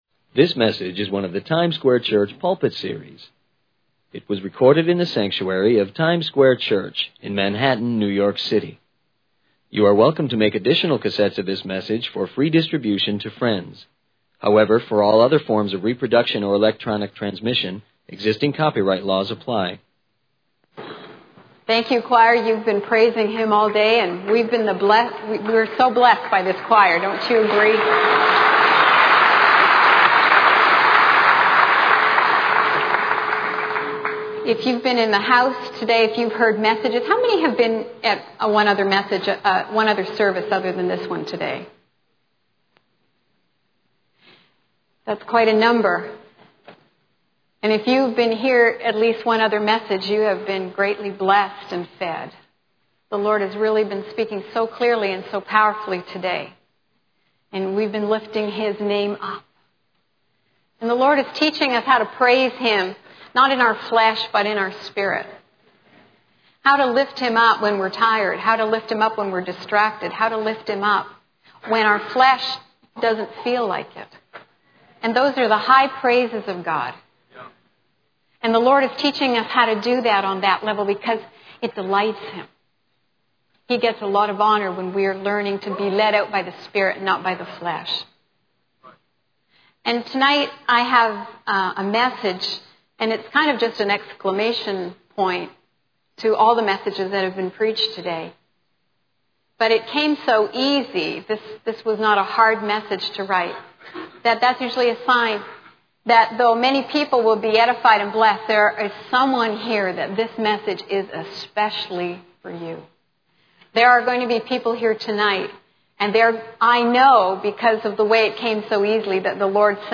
In this sermon, the speaker begins by praising the choir for their powerful worship.
It was recorded in the sanctuary of Times Square Church in Manhattan, New York City.